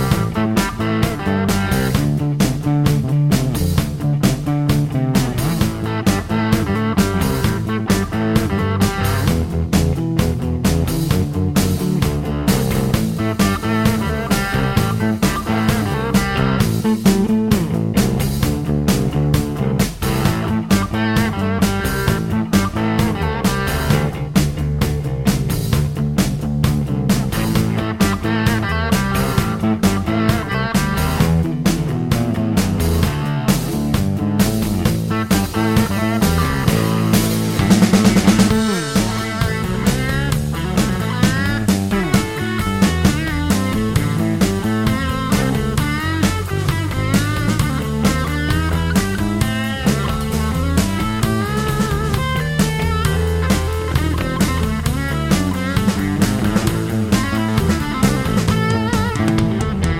Full length Blues 4:07 Buy £1.50